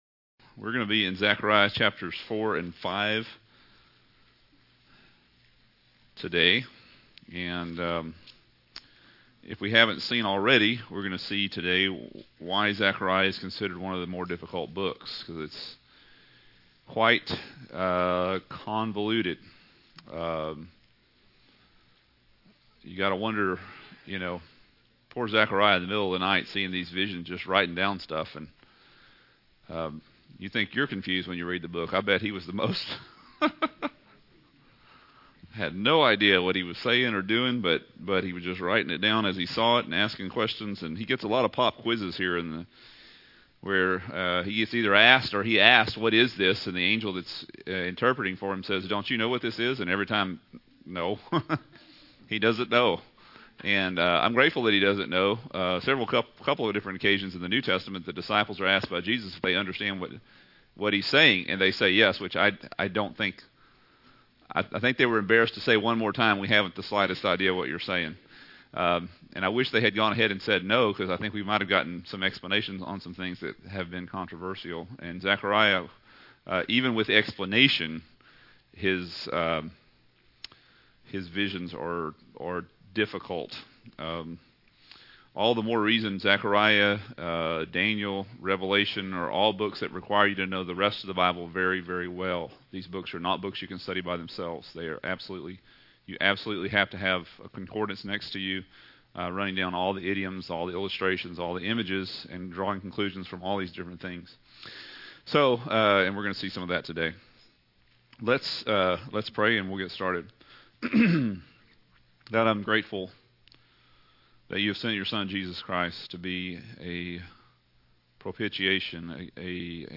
Zechariah-Lesson-3.mp3